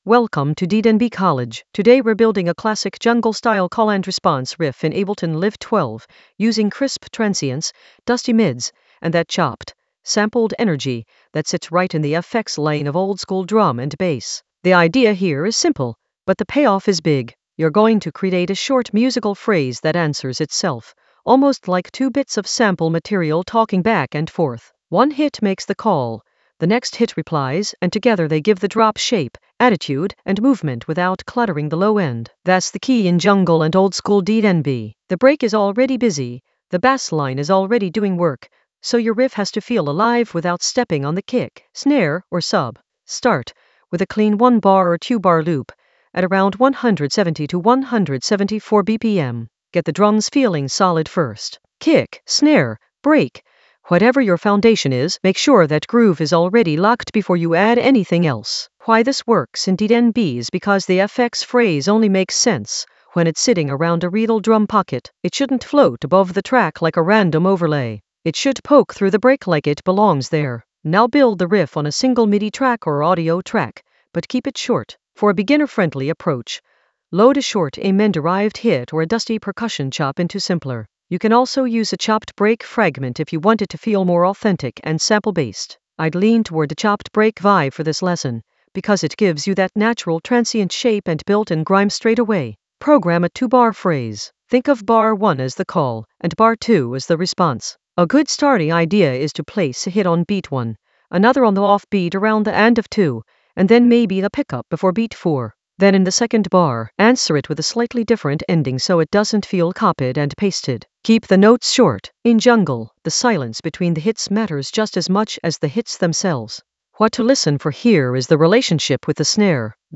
An AI-generated beginner Ableton lesson focused on Route an Amen-style call-and-response riff with crisp transients and dusty mids in Ableton Live 12 for jungle oldskool DnB vibes in the FX area of drum and bass production.
Narrated lesson audio
The voice track includes the tutorial plus extra teacher commentary.